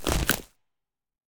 creaking_unfreeze1.ogg